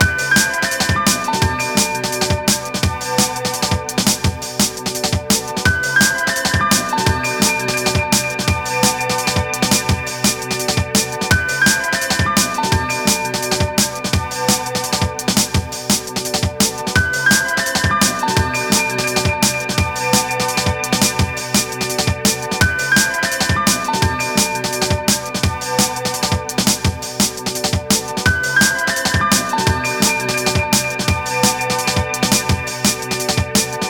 TRIP-HOP